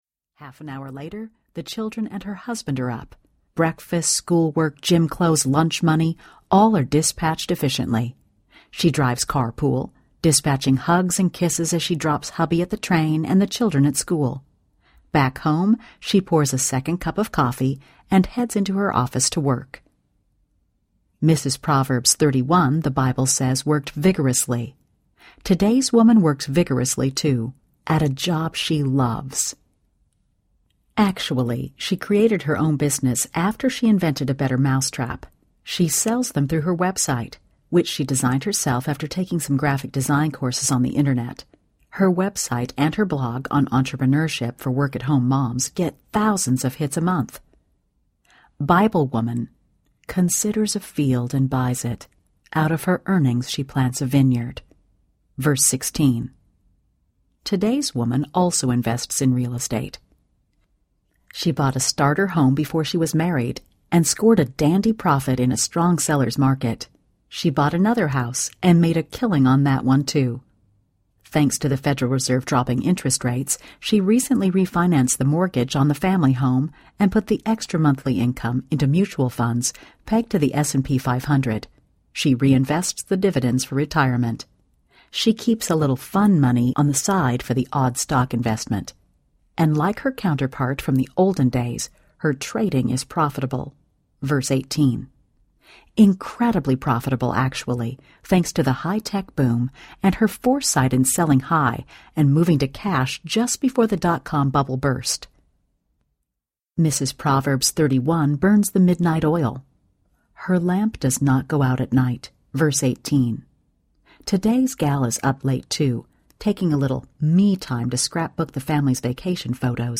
When Did My Life Become a Game of Twister? Audiobook
Narrator